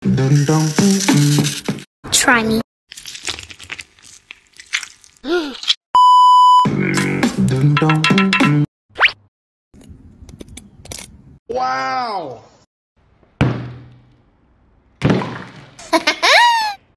ASMR Enjoy The Sleepy Soapy sound effects free download